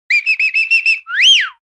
Whistle3.wav